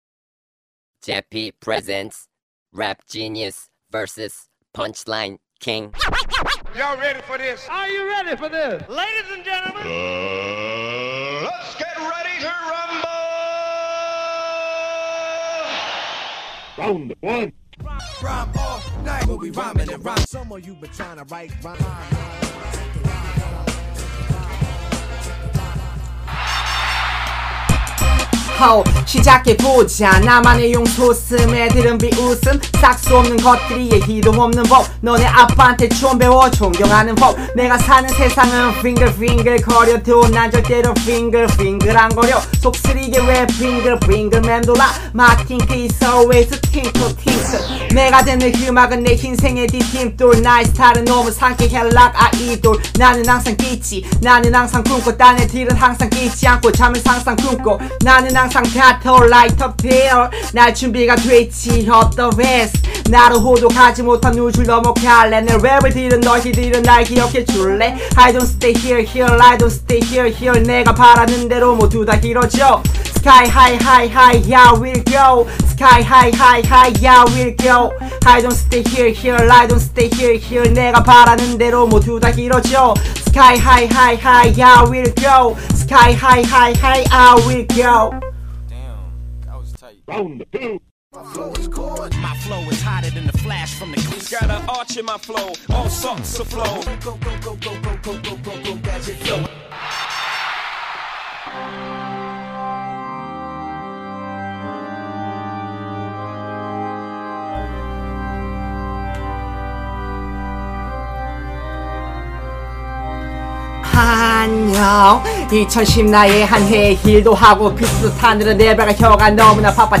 첫번째 벌스는 라임을 중점, 두번째 벌스는 플로우를 중점으로 랩했습니다.